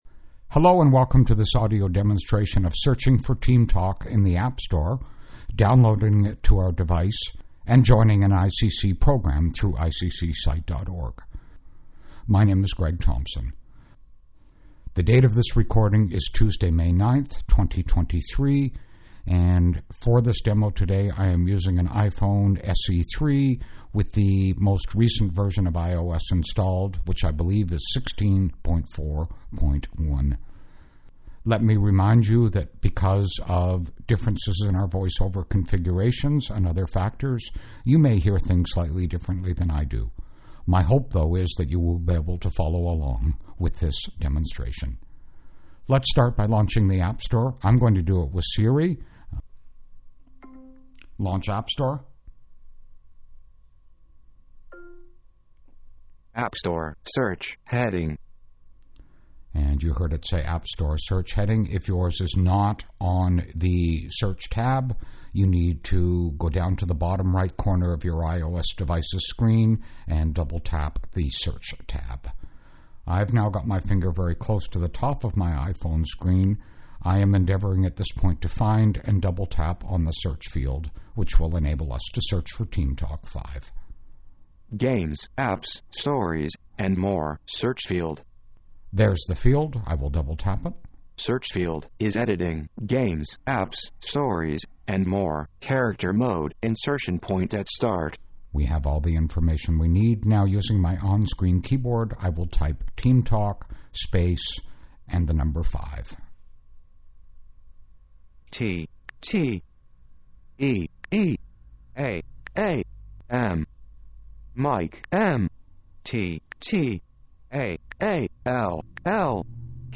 Through a combination of explanatory text and audio demonstrations, we want to make it as easy as possible for you to get the most out of Team Talk and be blessed by all that ICC has to offer.